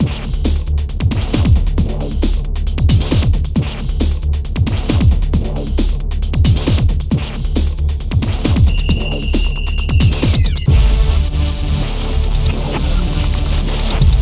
31 Futurefunk-b.wav